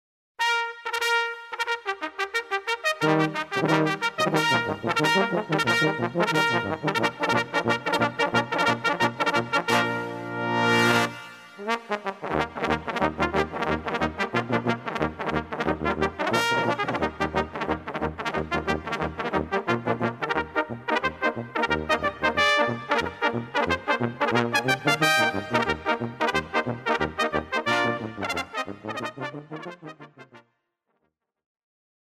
Besetzung: Kleine Blasmusik-Besetzung